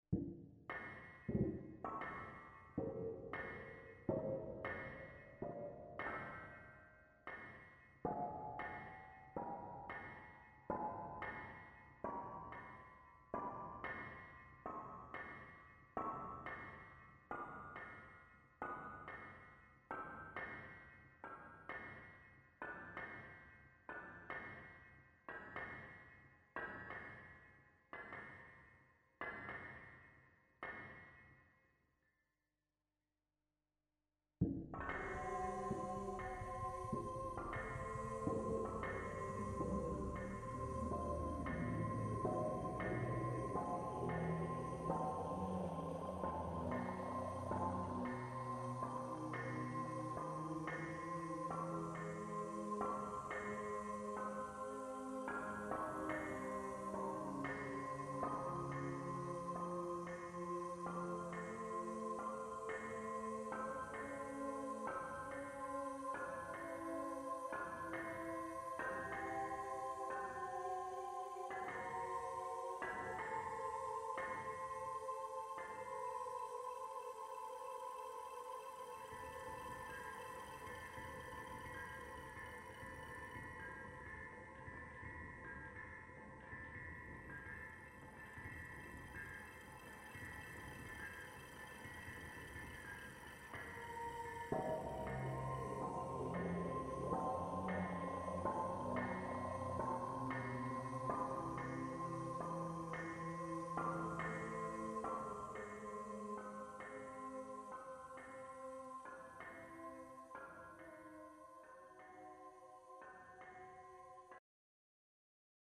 5.......Váhy Počítač převádí pohyb vertikálních a horizontálních objektů na hudbu - pohyb kyvadla - flétna jejíž tón rozhoupá misky laboratorních vah - vlastní hard - a software